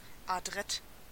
Ääntäminen
IPA: /pʁɔpʁ/